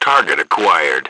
H_soldier1_19.wav